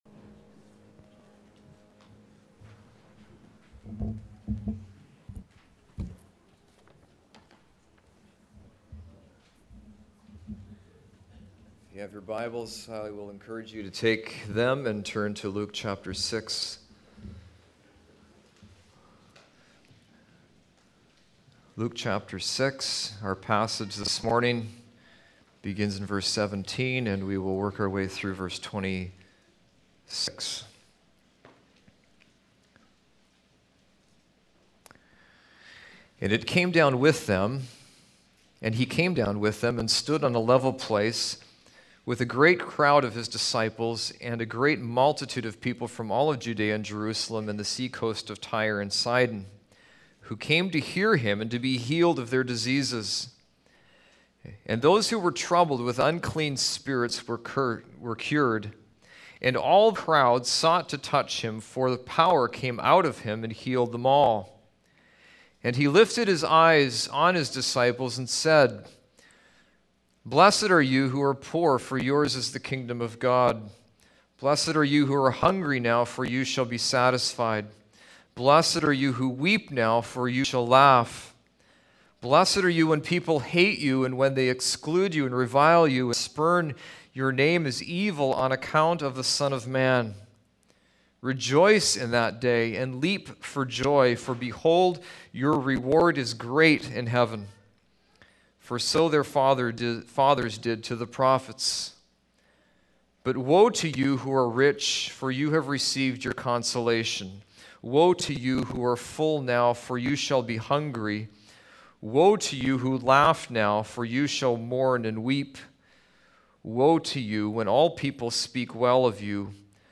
A Sermon on the Good Life? (Luke 6:17-26)